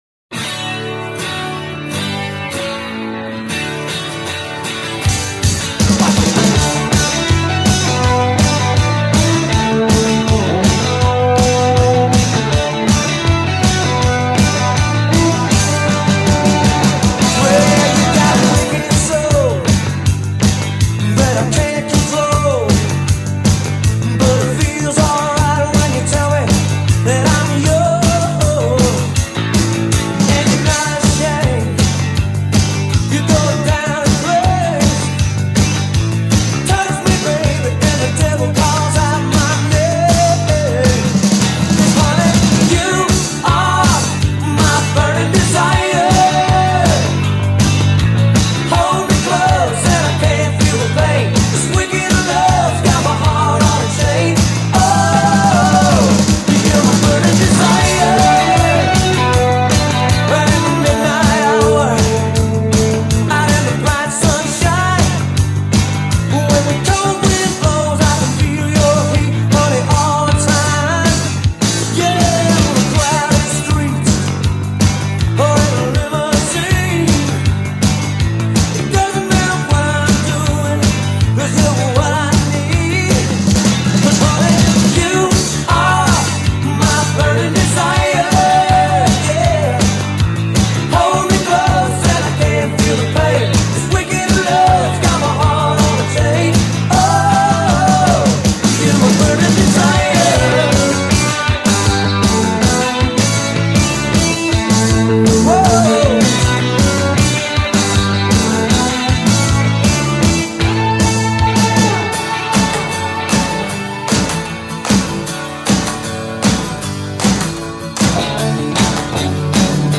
rockin’, rowdy
Category: Alternative, Classic Rock, Song of the Day